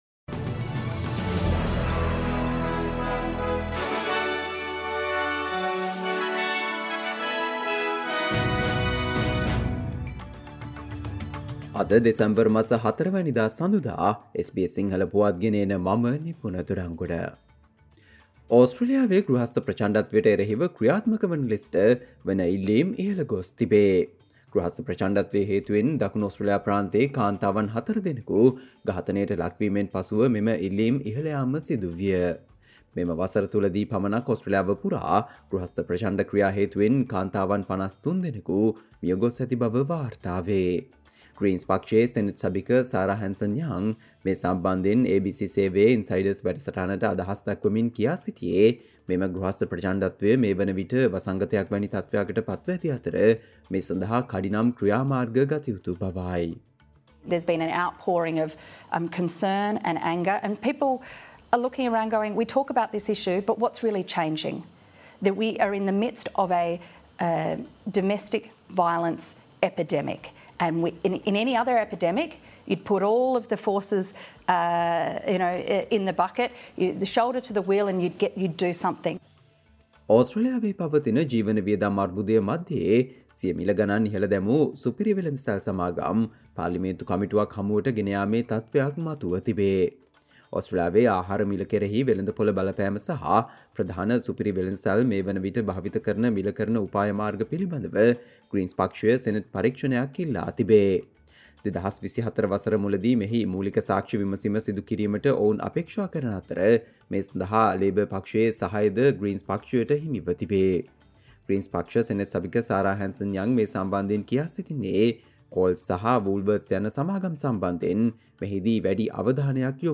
Australia news in Sinhala, foreign and sports news in brief - listen, Monday 04 December 2023 SBS Sinhala Radio News Flash